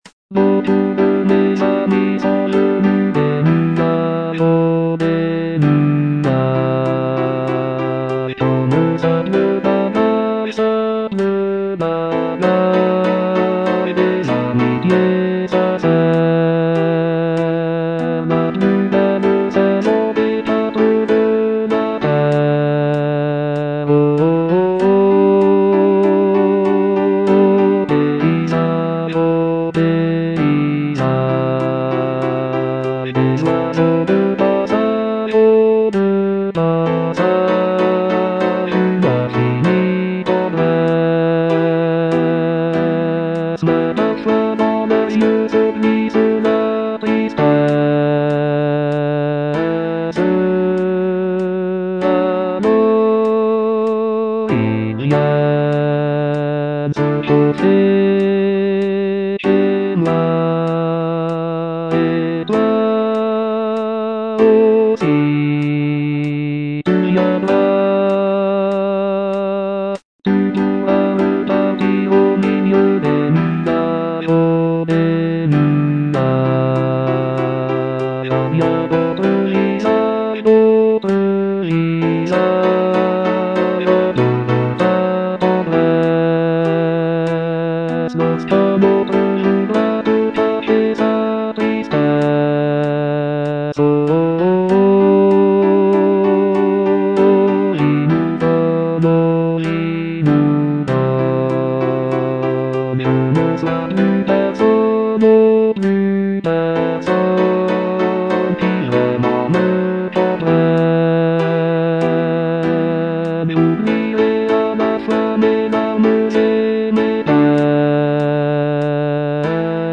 Bass II (Voice with metronome)
charming piece for choir